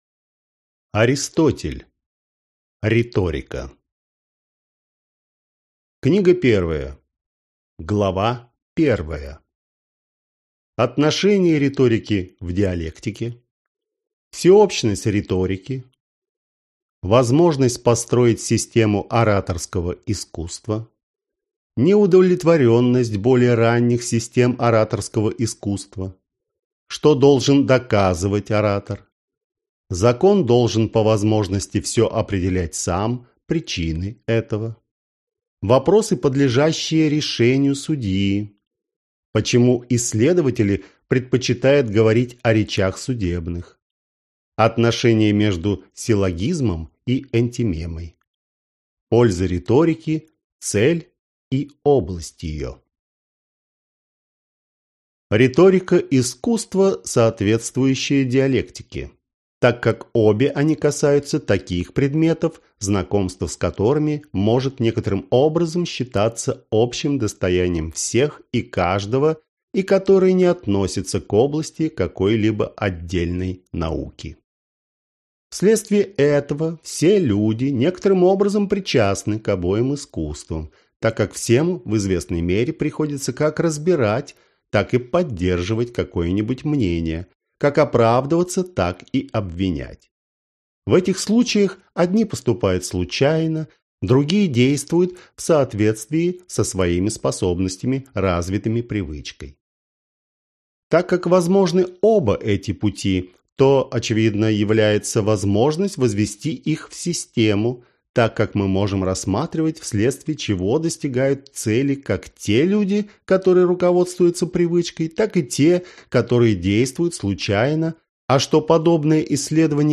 Аудиокнига Риторика | Библиотека аудиокниг